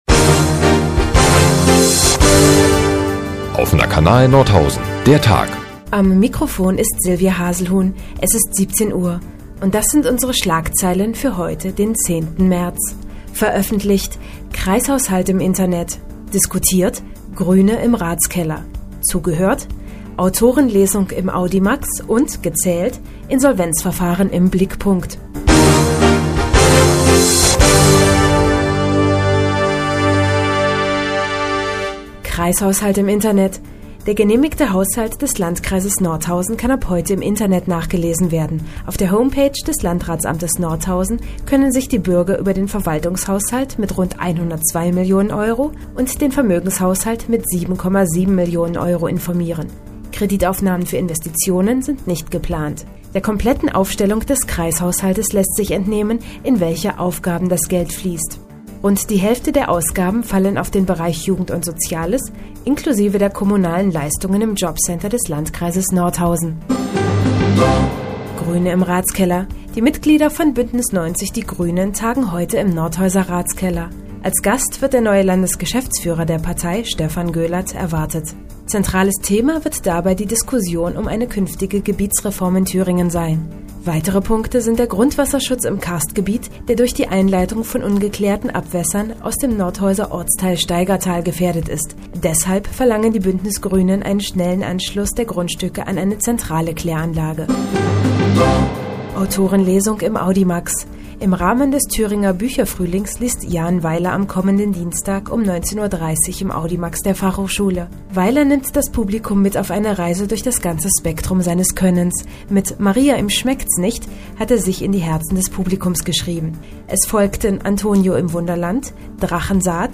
10.03.2011, 17:00 Uhr : Seit Jahren kooperieren die nnz und der Offene Kanal Nordhausen. Die tägliche Nachrichtensendung des OKN ist jetzt hier zu hören.